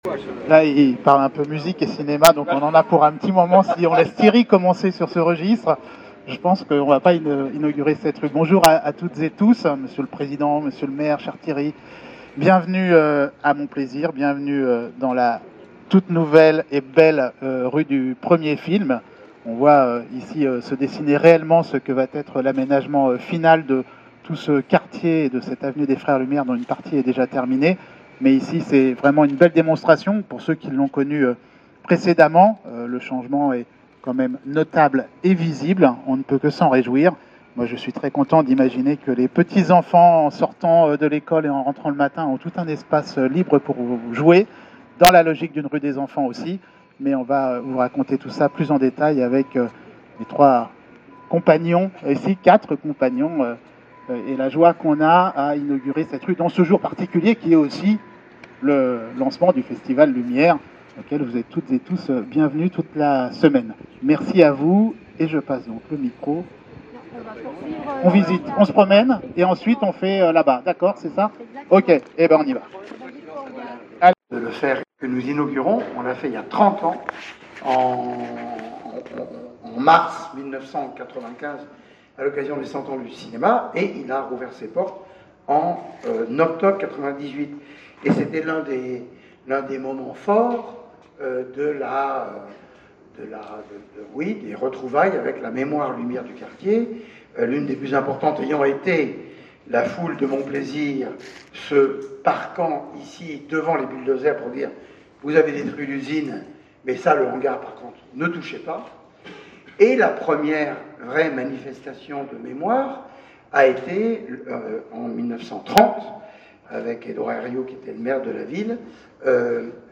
Inauguration de la rue du Premier Film après travaux